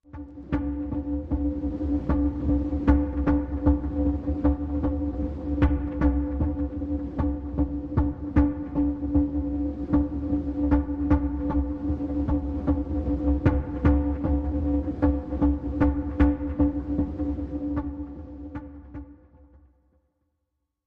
Internal Polygraph, Machine, Tension Pulse, Repeating Edge